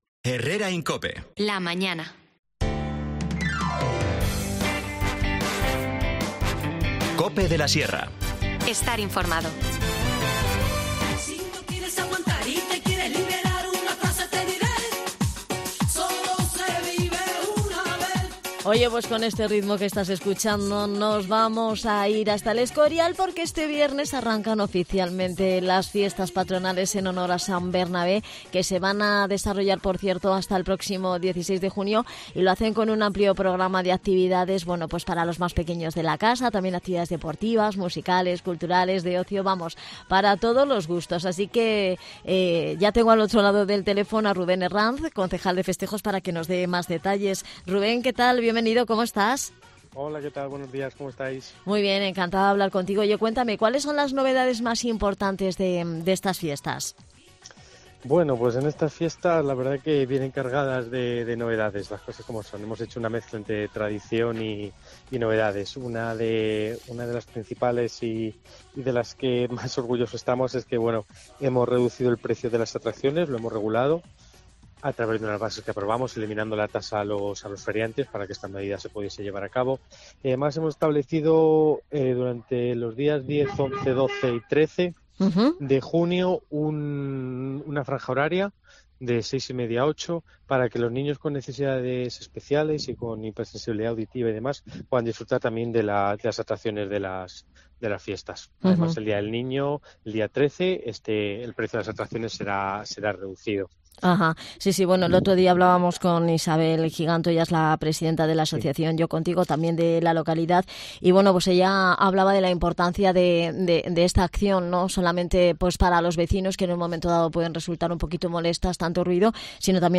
Charlamos con Rubén Herranz, concejal de Festejos